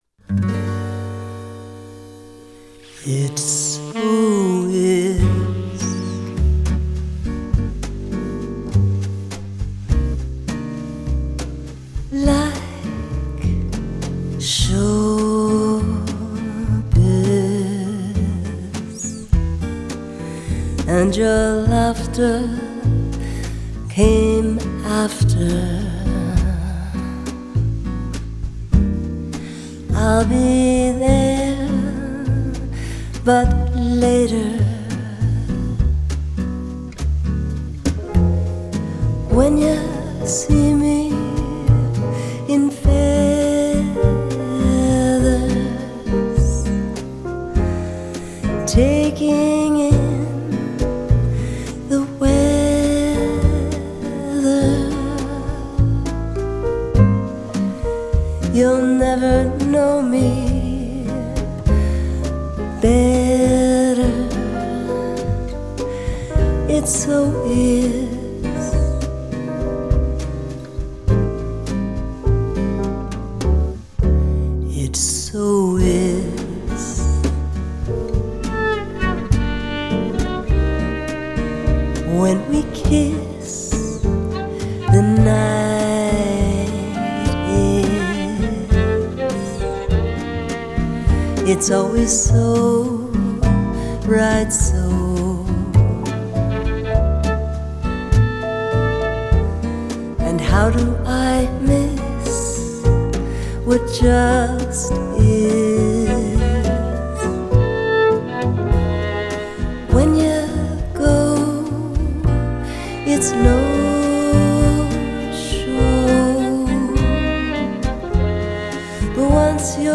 saxophone
mandolin